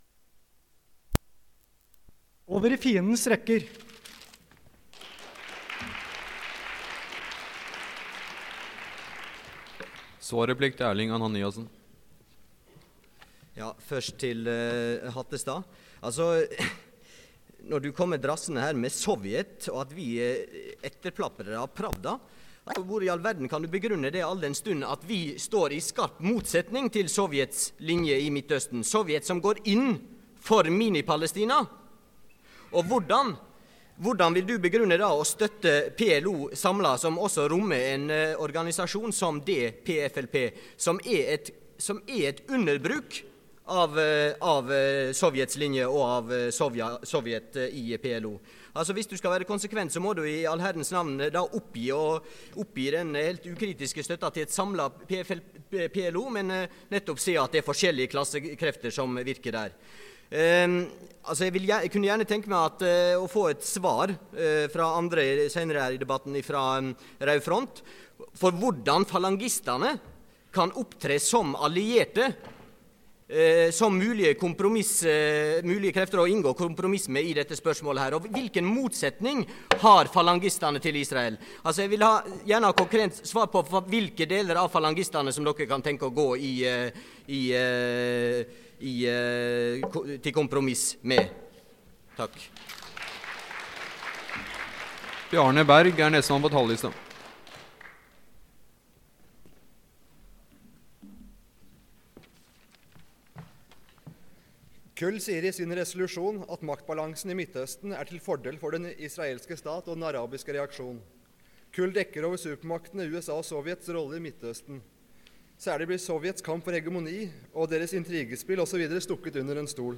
Det Norske Studentersamfund, Generalforsamling, 26.11.1977 (fil 2-4:15)